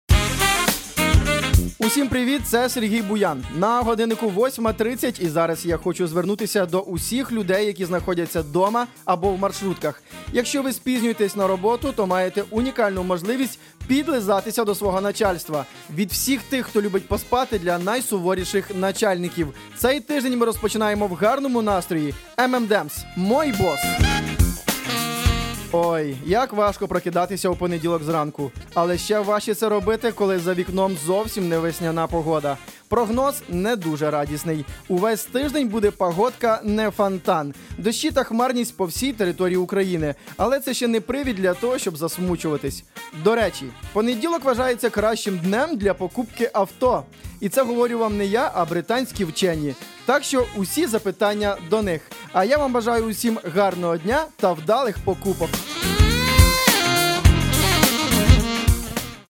демо голоса
Чоловіча